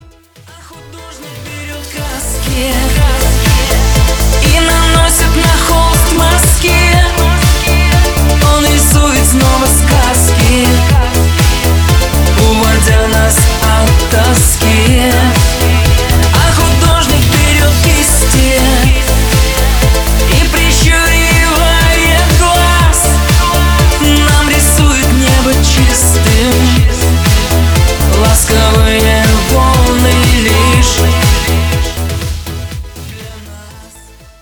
• Качество: 320, Stereo
поп
громкие
душевные